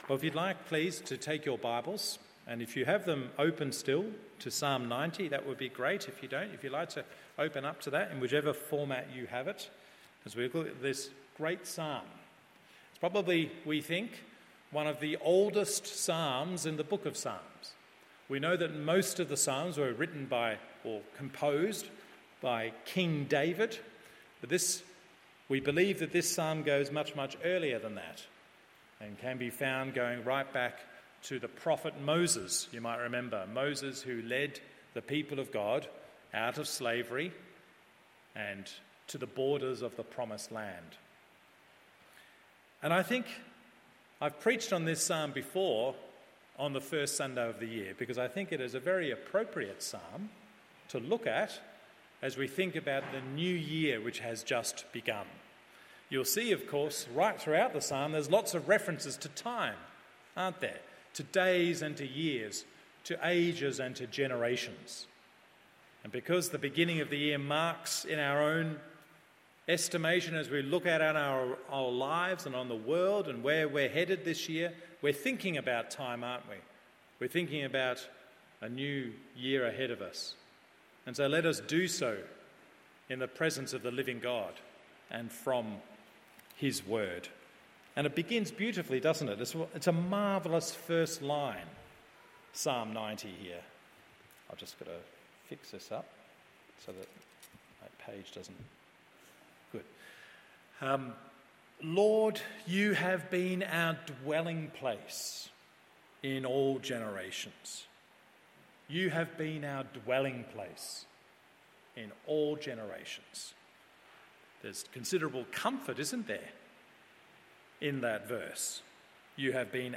MORNING SERVICE Psalm 90…